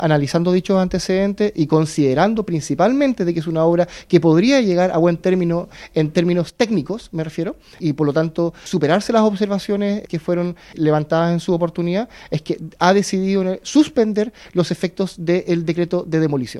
El alcalde (s) de Valdivia, el administrador municipal Cristian Oñate, indicó que suspendieron la orden de demoler, porque además de las explicaciones del privado, la obra puede ser retomada.